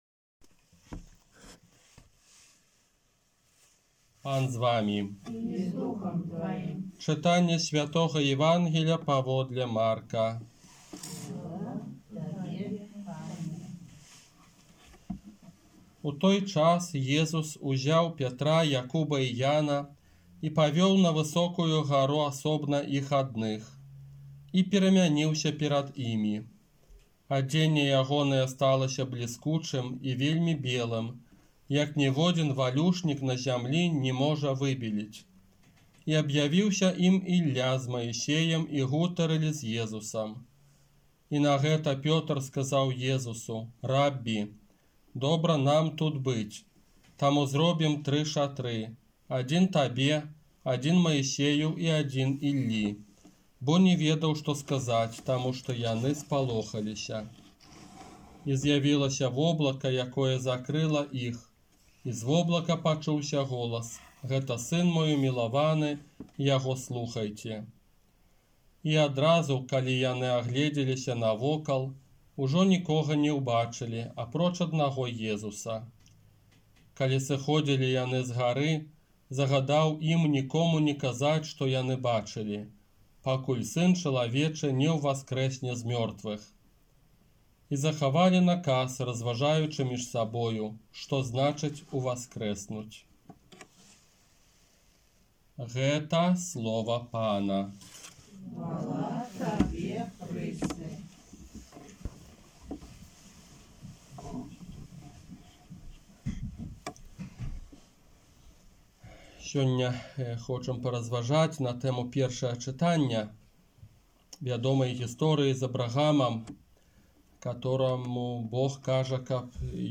Казанне на другую нядзелю Вялікага Паста